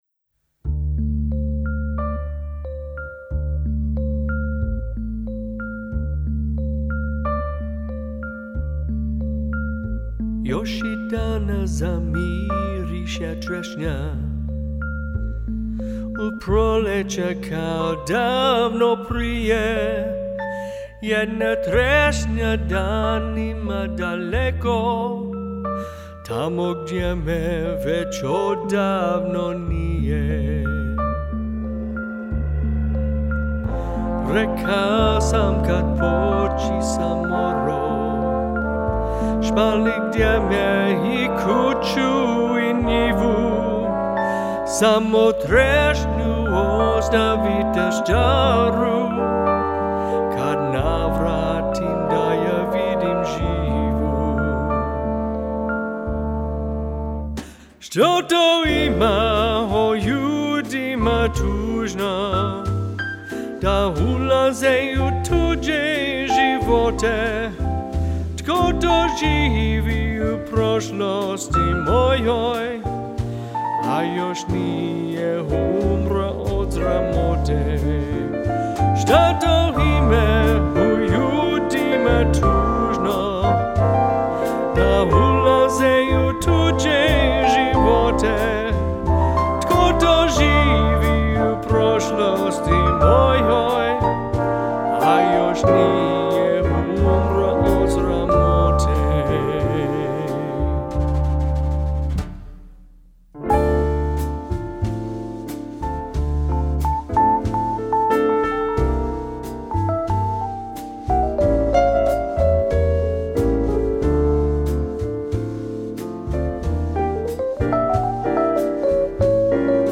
vocalist
fender rhodes
piano.